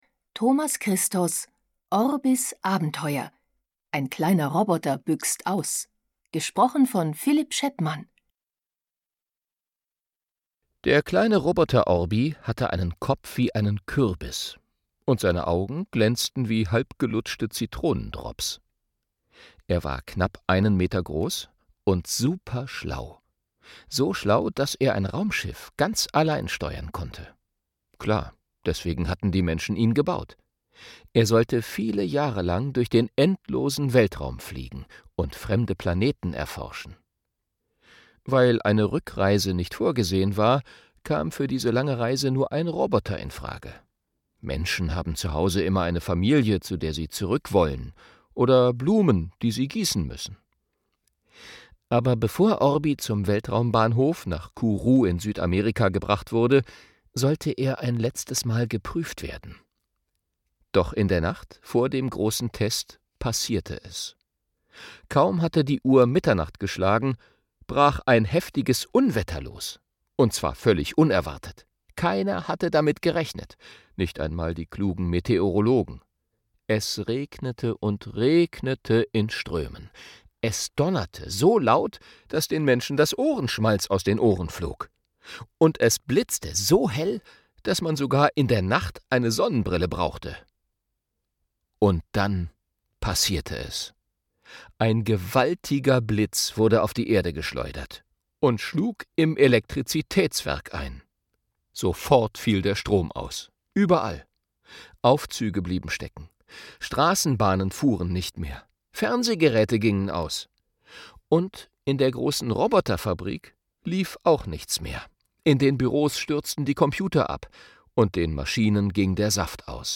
Lesung für Kinder/Jugendliche